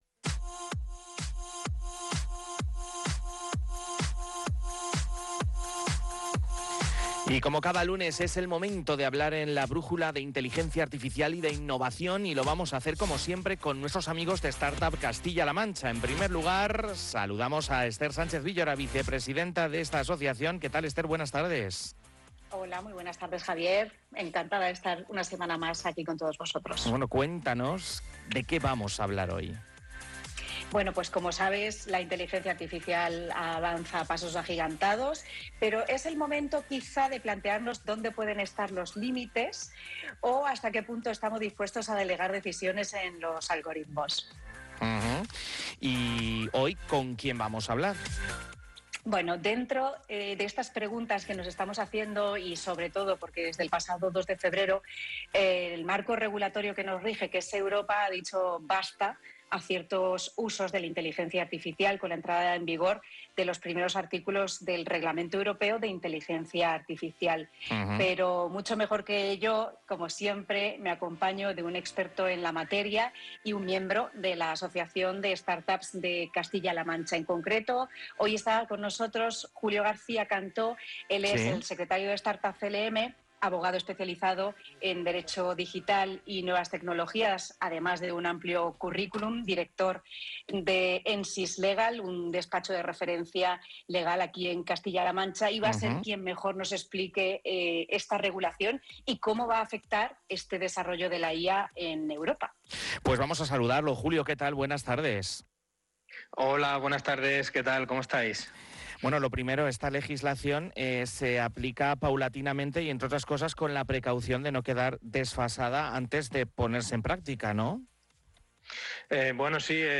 En el programa de radio Rumbo Startup, dentro de La Brújula de Castilla-La Mancha de Onda Cero, se aborda este tema con un toque fresco y desenfadado.